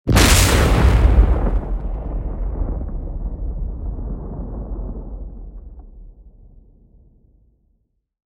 دانلود آهنگ رعدو برق 28 از افکت صوتی طبیعت و محیط
جلوه های صوتی
دانلود صدای رعدو برق 28 از ساعد نیوز با لینک مستقیم و کیفیت بالا